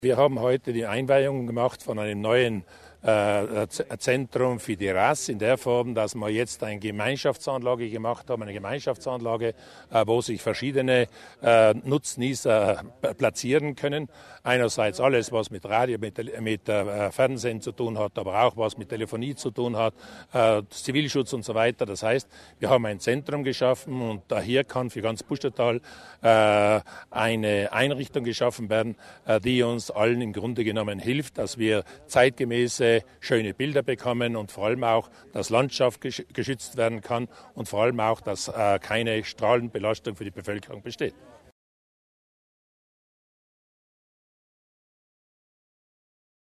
Landesrat Laimer zur Umweltfreundlichkeit der Einrichtung